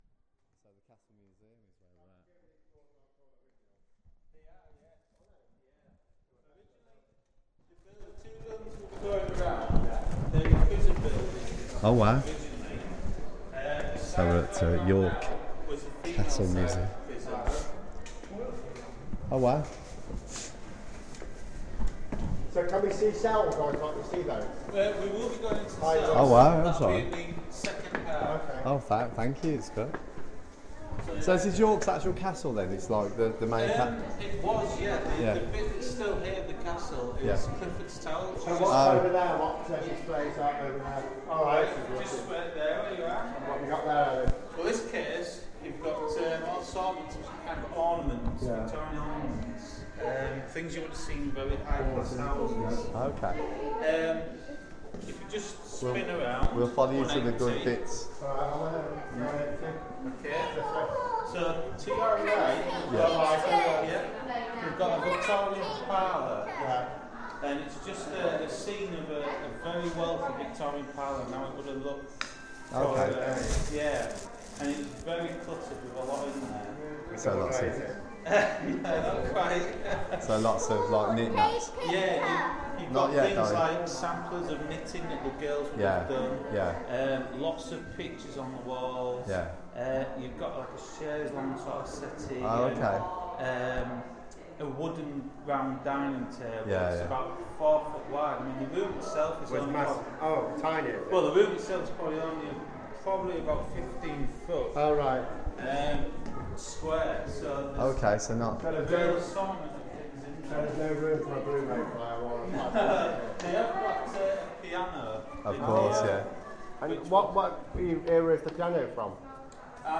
Join us for a tour of this fascinating look at the way we live and have lived our lives through the ages. Includes the cell in which Dick Turpin and a cool recording of a Victorian Street Piano.